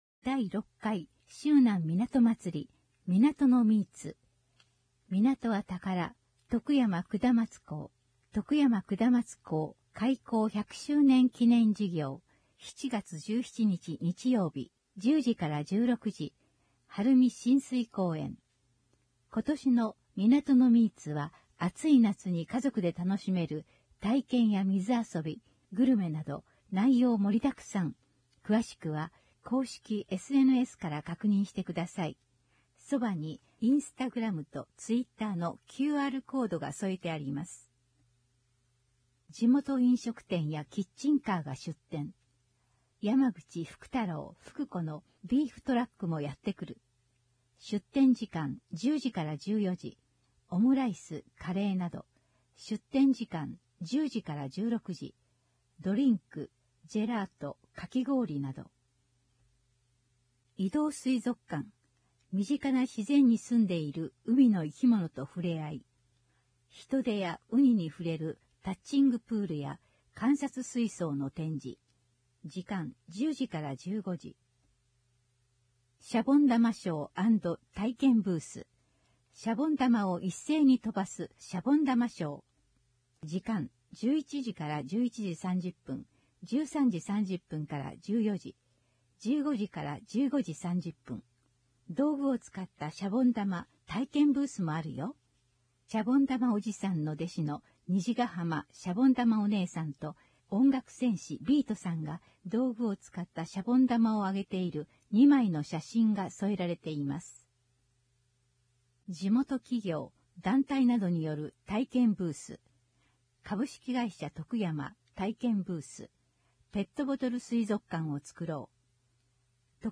音訳広報